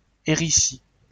来自 Lingua Libre 项目的发音音频文件。 语言 InfoField 法语 拼写 InfoField Héricy 日期 2019年1月31日 来源 自己的作品